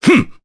Nicx-Vox_Attack1_jp.wav